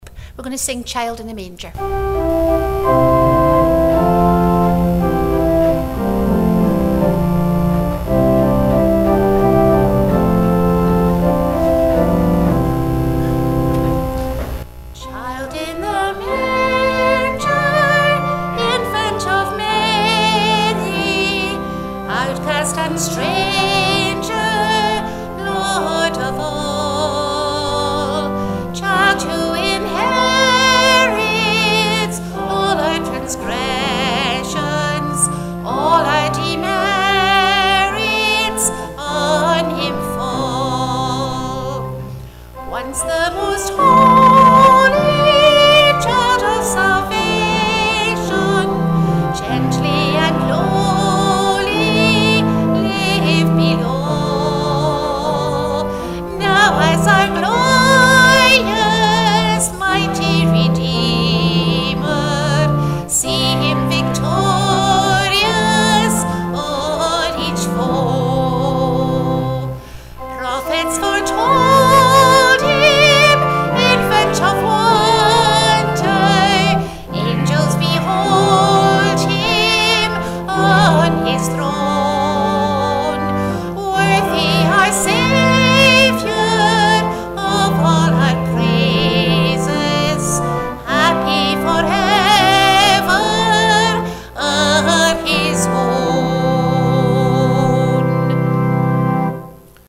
Christmas Eve Afternoon Family Service